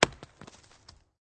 fallingcakes.ogg